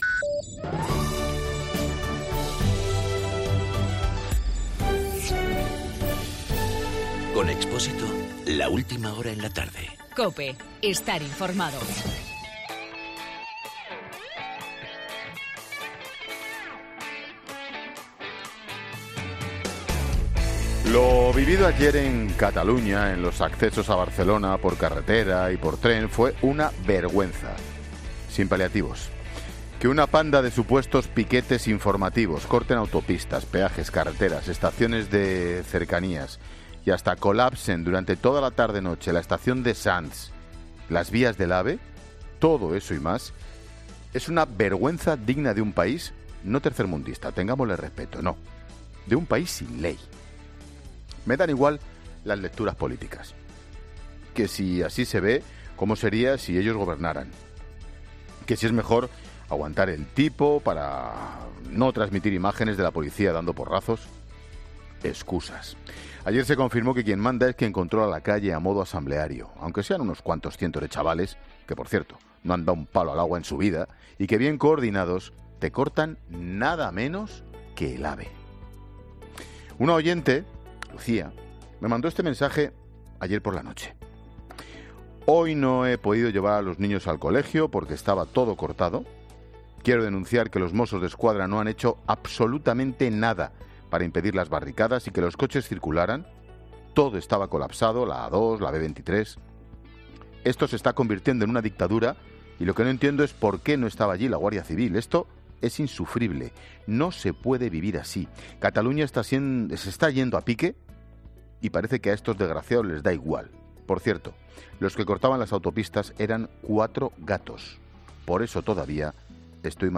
Monólogo de Expósito
El comentario de Ángel Expósito tras la huelga general en Cataluña.